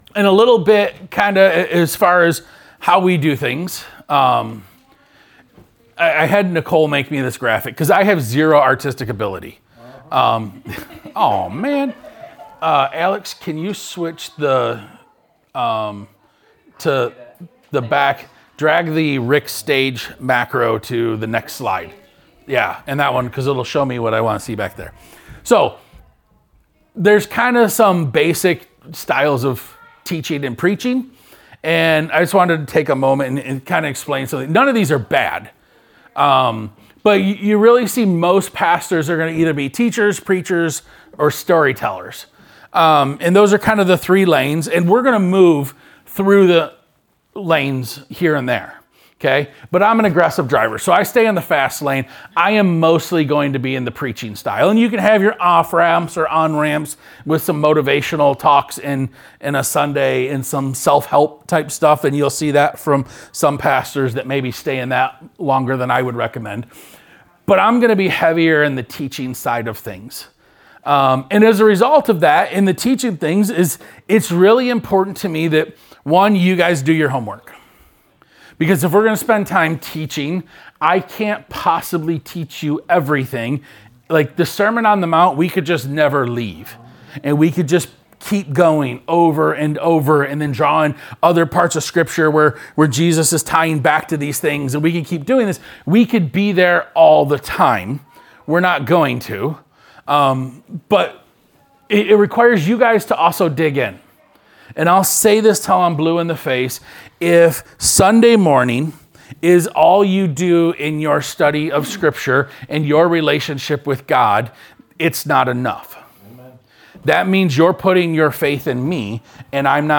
Sermon 11-2.mp3